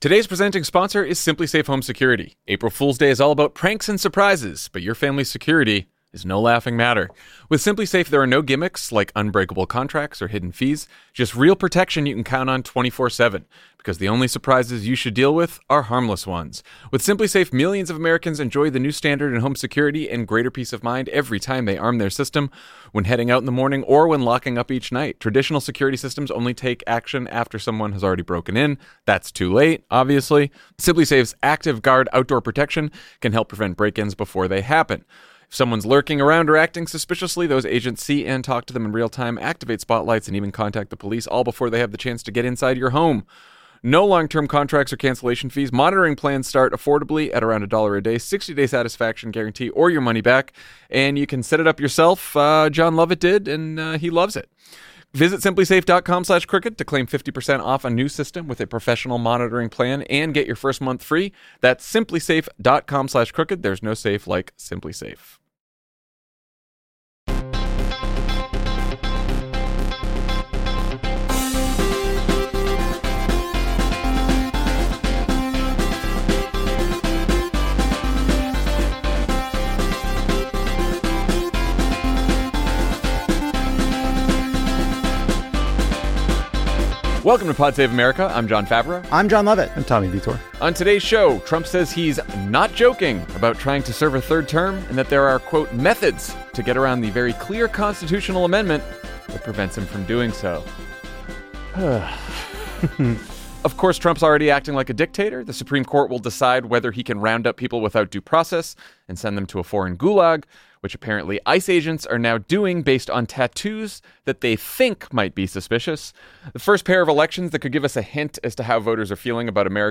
Donald Trump's long-promised "Liberation Day" of insane new tariffs approaches, but what's his plan for the global trade war he's promising to start? Jon, Lovett, and Tommy discuss all the latest madness, including Trump's new hints that he'll serve a third term, the galling new details about Alien Enemy Act deportations, and Elon Musk buying votes in the Wisconsin judicial race. Then, Jon sits down with Arizona Senator Ruben Gallego to talk about how Democrats can fight back against Trump and how we can win again in states like his.